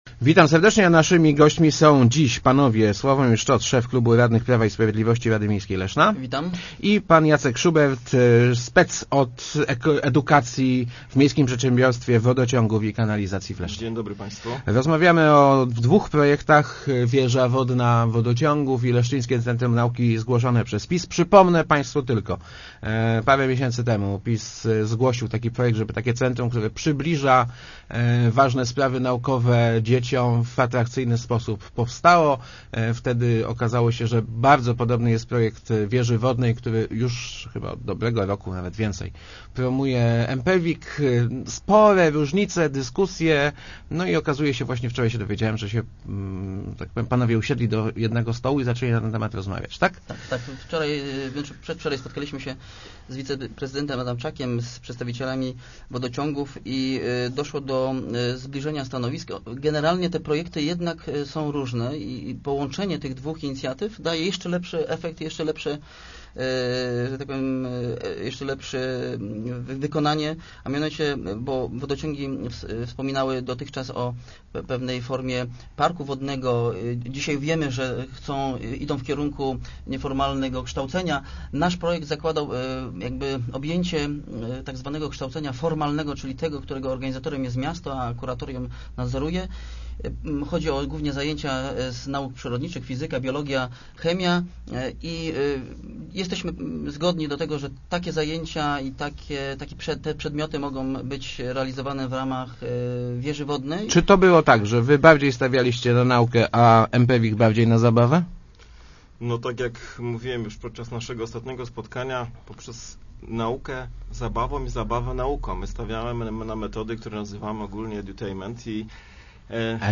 27.10.2009. Radio Elka